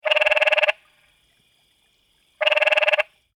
harenicolorsinglecall.mp3